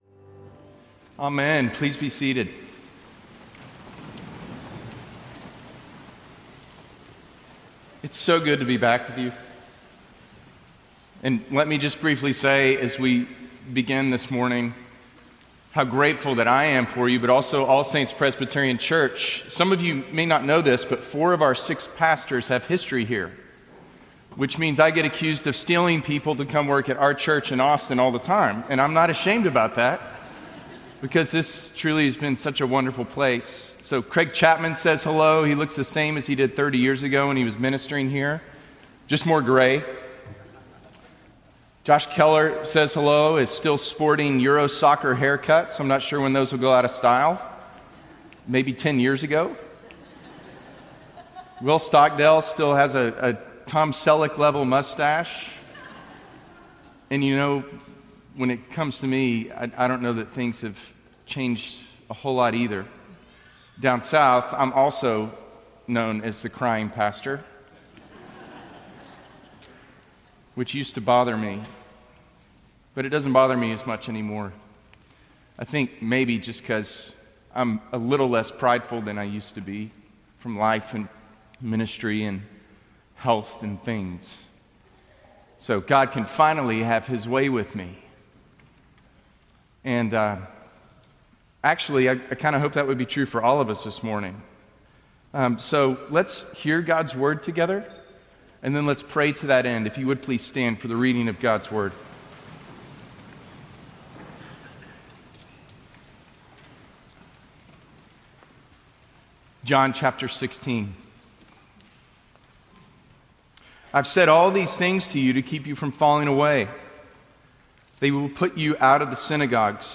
Latest Sermon